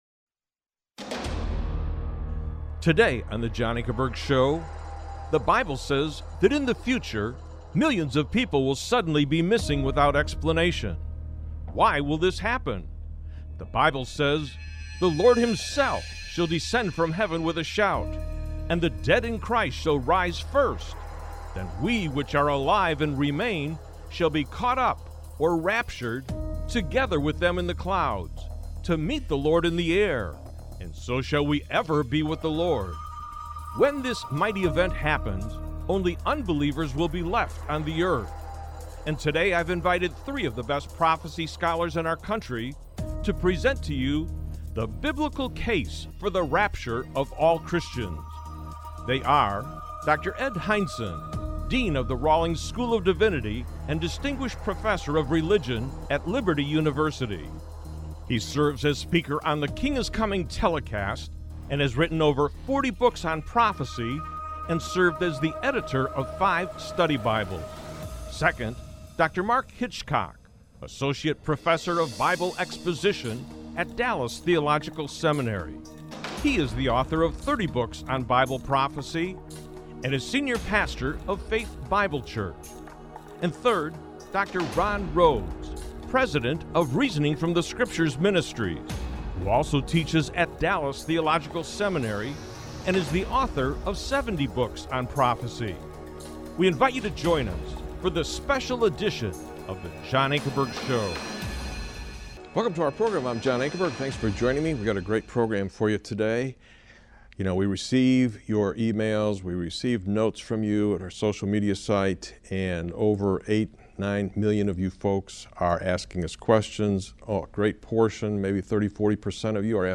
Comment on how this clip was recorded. The formats of choice for this apologetics ministry are informal debates between representatives of differing belief systems, and documentary-styled presentations on major issues in society to which the historic Christian faith has something of consequence to say.